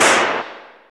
SI2 SHOT 0JR.wav